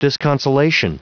Prononciation du mot disconsolation en anglais (fichier audio)
Prononciation du mot : disconsolation